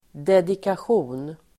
Ladda ner uttalet
dedikation substantiv, dedication Uttal: [dedikasj'o:n] Böjningar: dedikationen, dedikationer Definition: det att tillägna någon något (the act of dedicating sth. to sby) Sammansättningar: dedikations|exemplar (inscribed copy)
dedikation.mp3